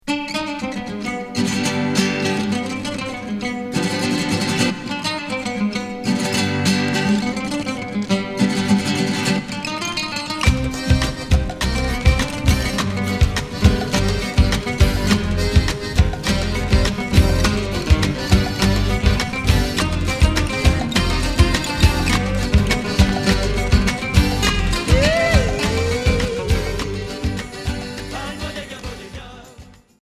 Folk Rock